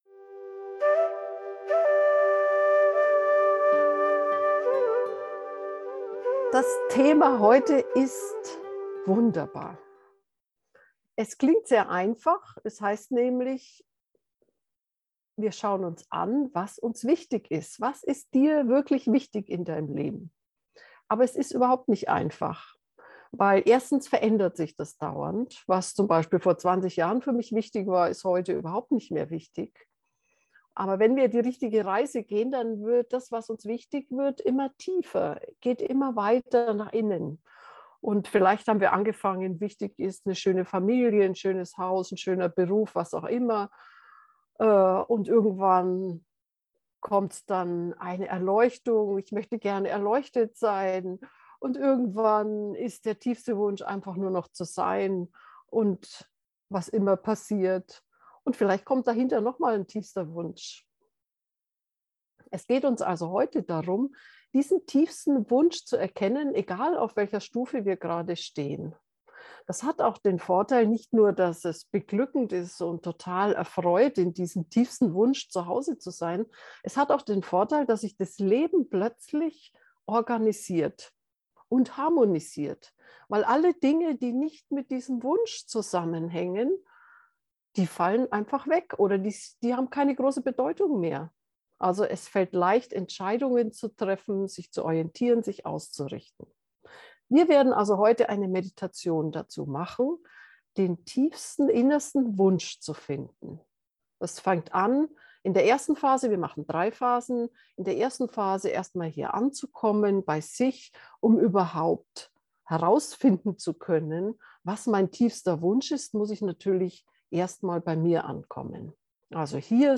werde-das-was-sehnsucht-gefuehrte-meditation-2